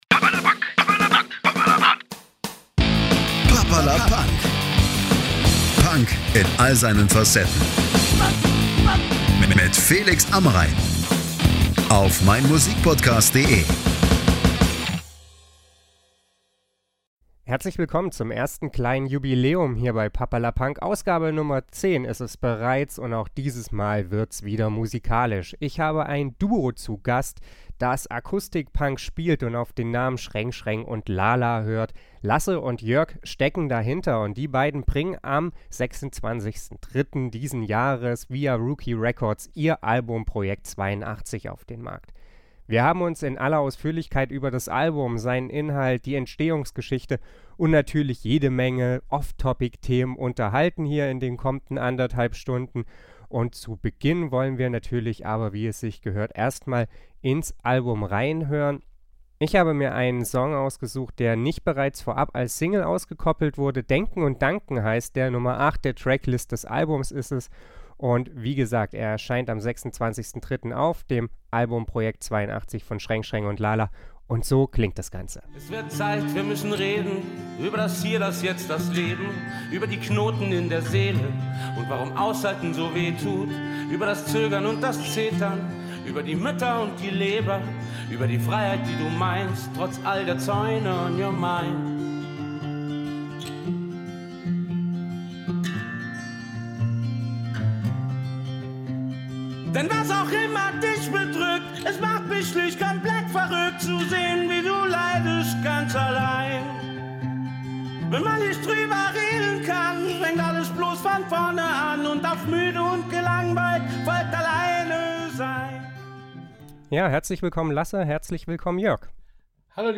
Der Podcast wurde am 9. März via StudioLink aufgezeichnet.